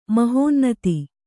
♪ mahōnnati